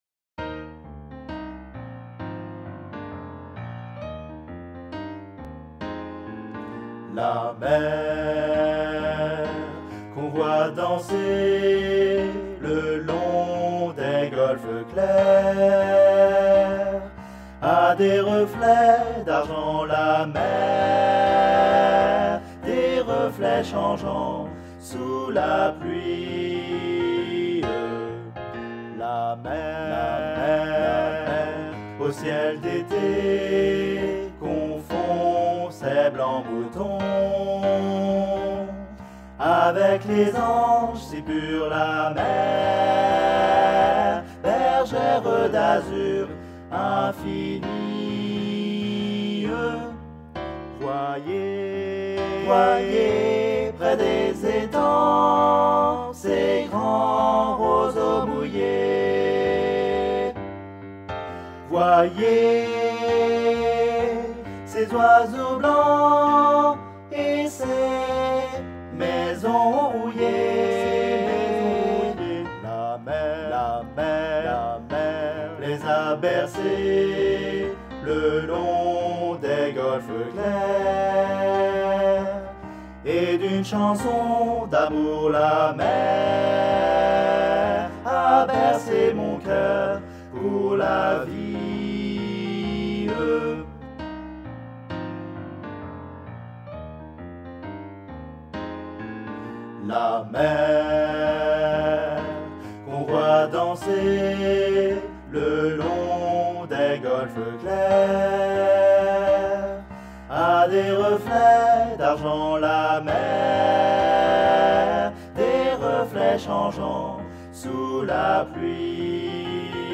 MP3 versions chantées
Tutti